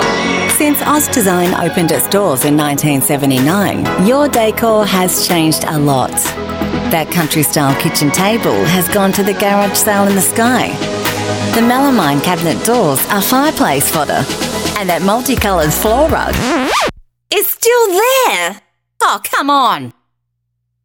female